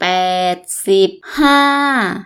_ bpaeaed _ sibb ∧ haa